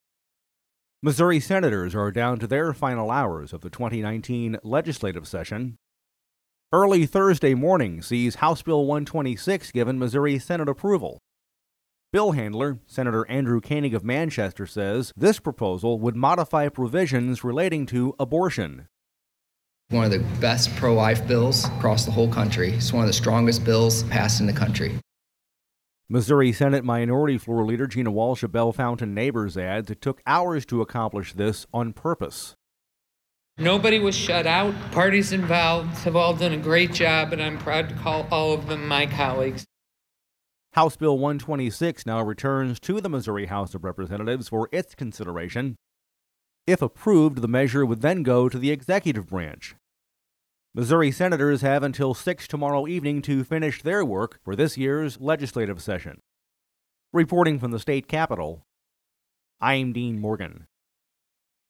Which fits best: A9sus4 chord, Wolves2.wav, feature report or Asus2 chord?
feature report